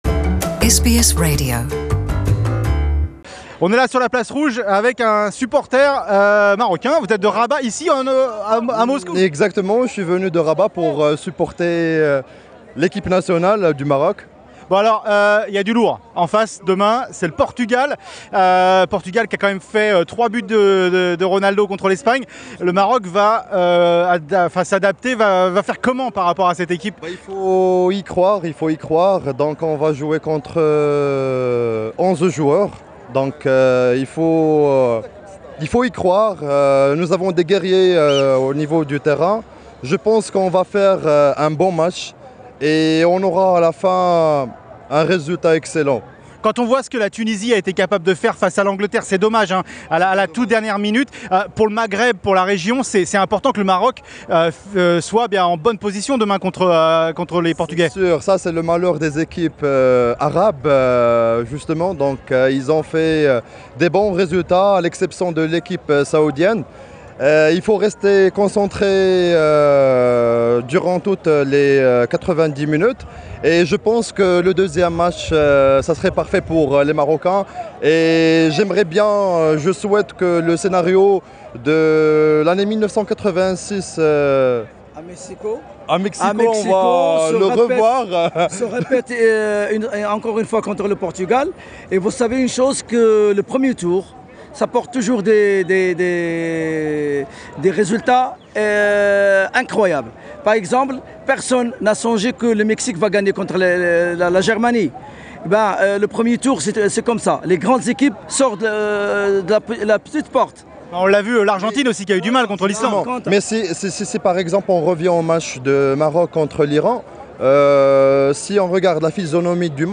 Rencontre avec les supporteurs du Maroc sur la Place Rouge.